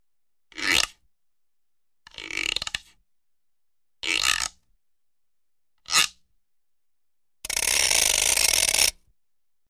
Звуки стиральной доски
Звук резких скрипов при использовании стиральной доски